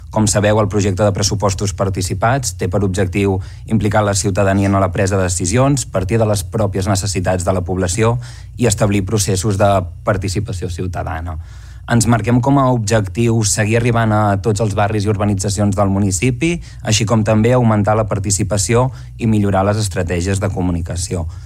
Així ho detallava el regidor dels pressupostos participats, Pere Garcia.